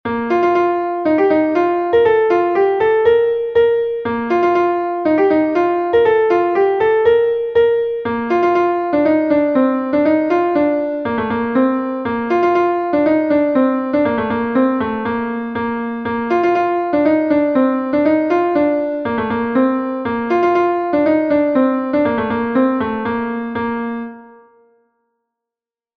Gavotenn Berne is a Gavotte from Brittany